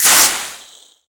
soda_bottle_start.ogg